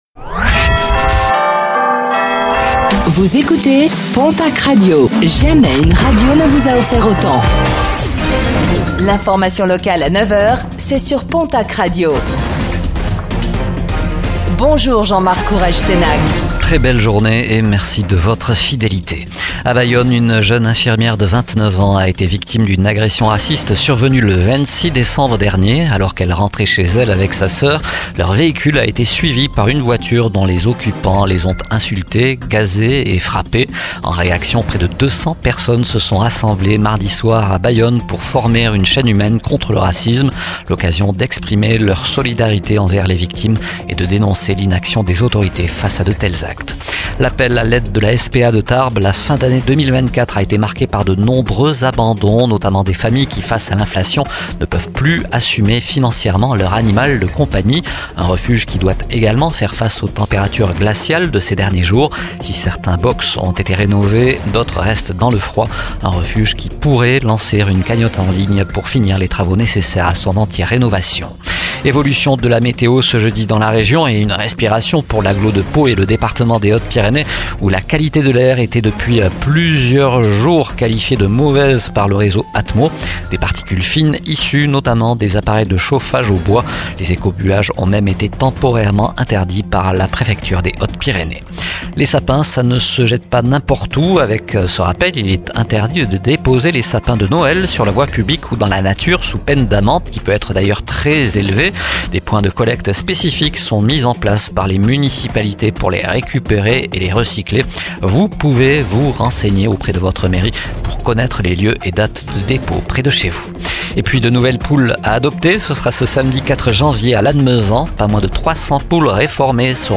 Réécoutez le flash d'information locale de ce jeudi 02 janvier 2025